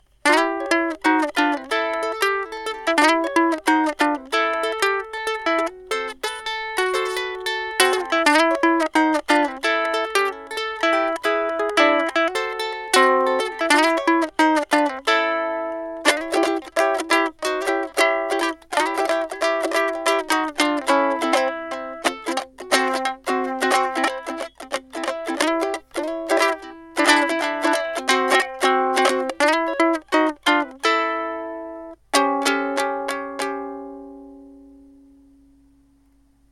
DIY Cigar Box Amp
diyamp.mp3